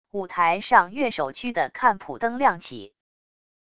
舞台上乐手区的看谱灯亮起wav音频